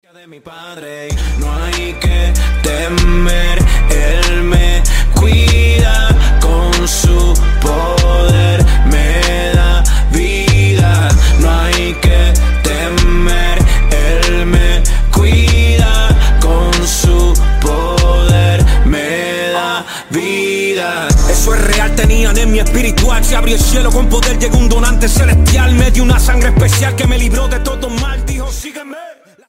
HipHop / Rock